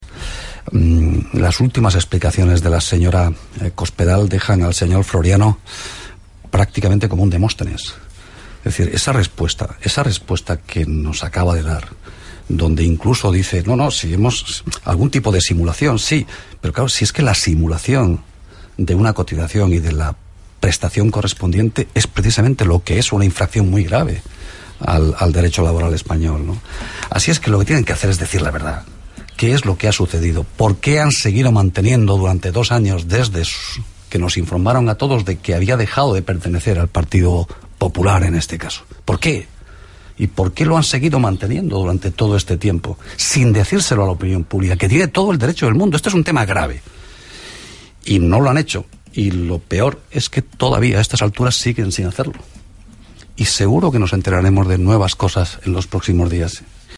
Declaraciones de Valeriano Gómez en RNE el 4/3/2013